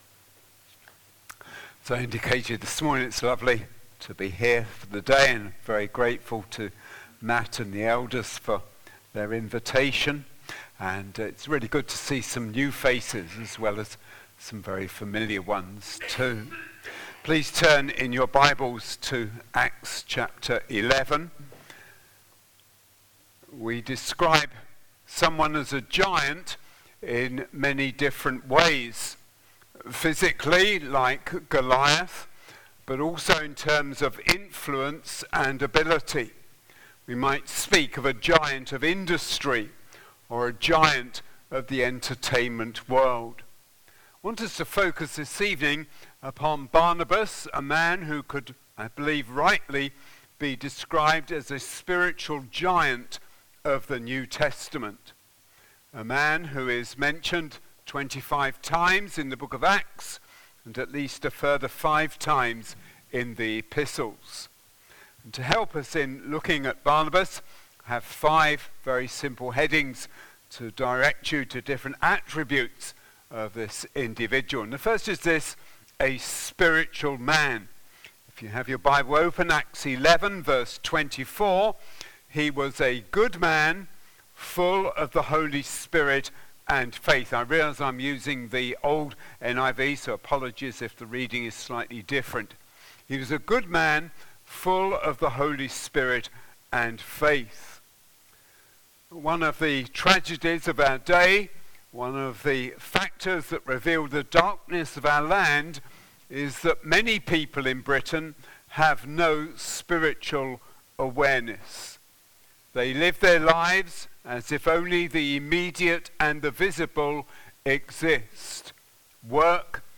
LABC Sermons Acta 11v19-30 Barnabas - He encouraged them Play Episode Pause Episode Mute/Unmute Episode Rewind 10 Seconds 1x Fast Forward 30 seconds 00:00 / 31:38 Subscribe Share RSS Feed Share Link Embed